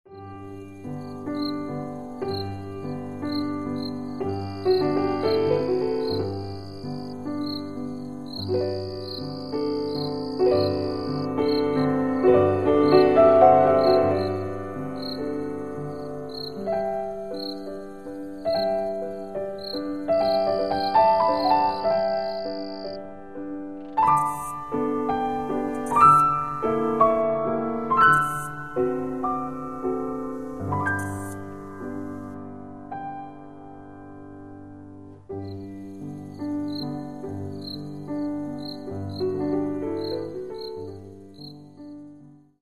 Каталог -> Другое -> Relax-piano, музыкальная терапия
звуки кузнечиков